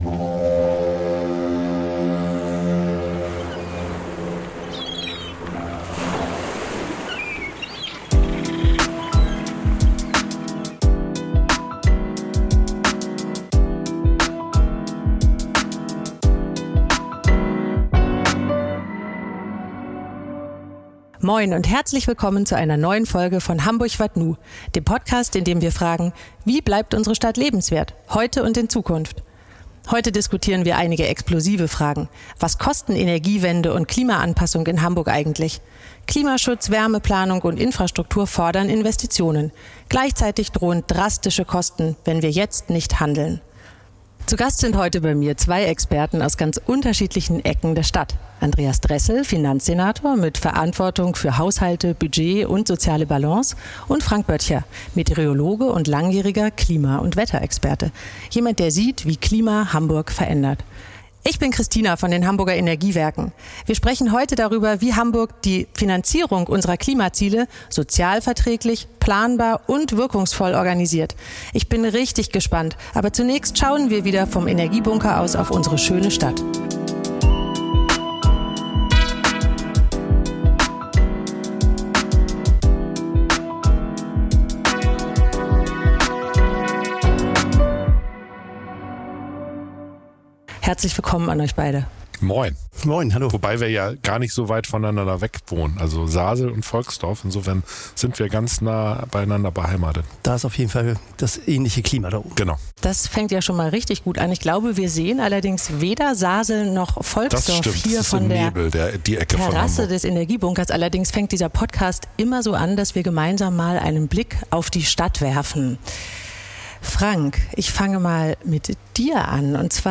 Aufgenommen im Energiebunker Wilhelmsburg mit Blick auf eine Stadt im Wandel, die heute Entscheidungen trifft, um auch morgen lebenswert zu bleiben.